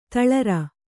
♪ taḷar